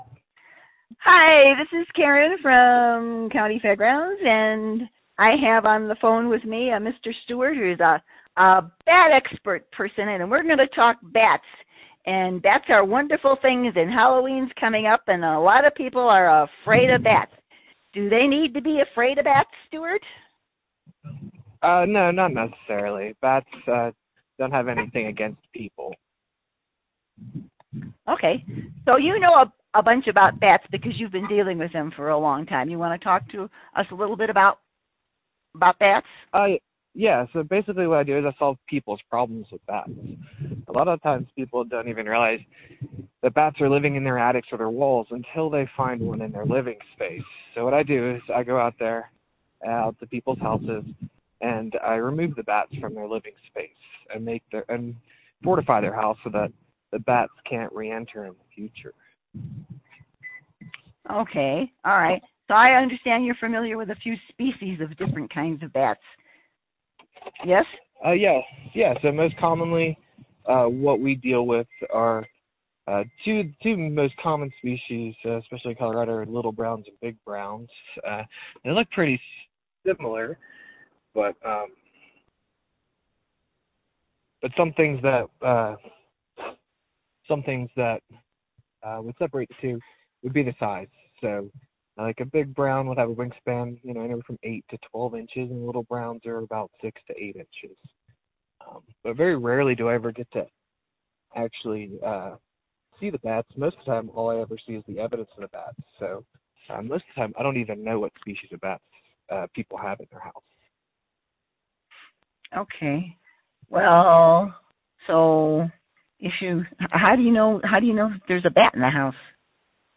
Interview Bat Wildlife Expert